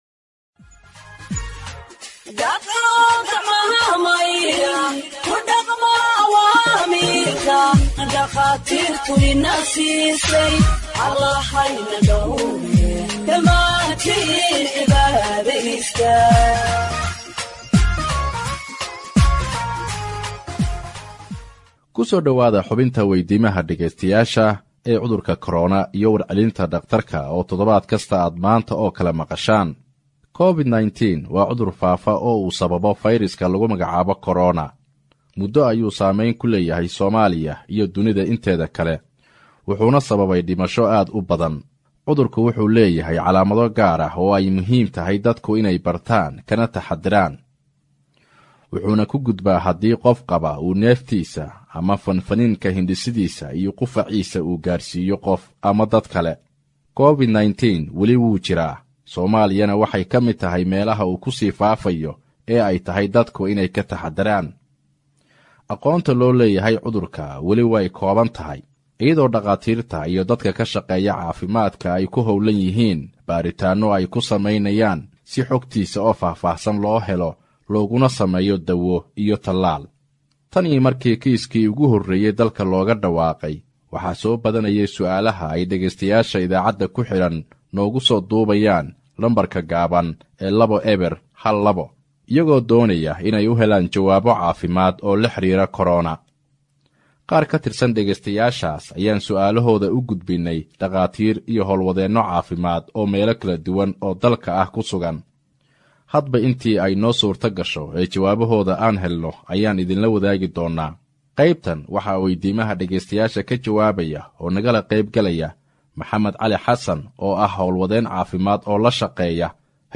HEALTH EXPERT ANSWERS LISTENERS’ QUESTIONS ON COVID 19 (62)
Radio Ergo provides Somali humanitarian news gathered from its correspondents across the country for radio broadcast and website publication.